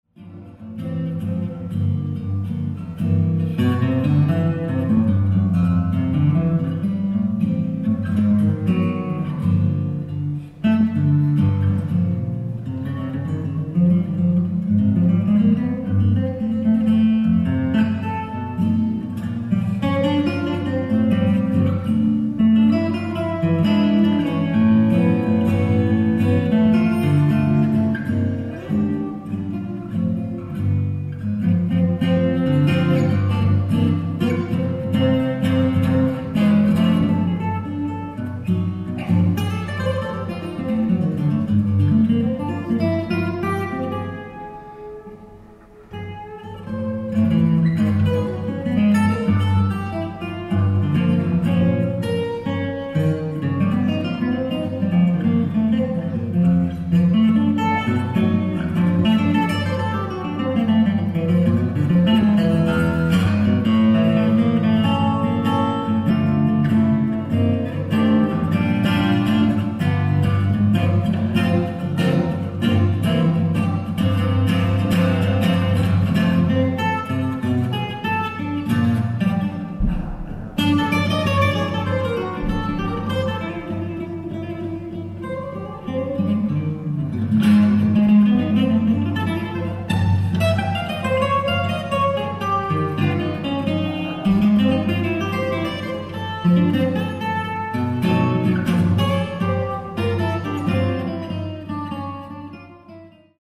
ライブ・アット・シャトークア・オーディトリアム、ボルダー、コロラド 08/02/2025
※試聴用に実際より音質を落としています。